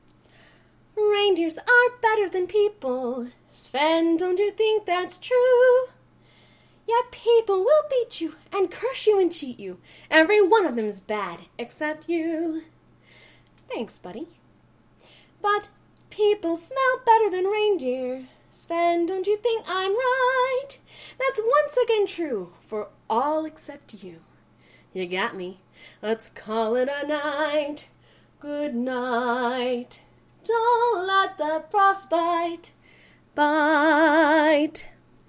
Here I am singing